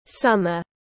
Mùa hè tiếng anh gọi là summer, phiên âm tiếng anh đọc là /ˈsʌm.ər/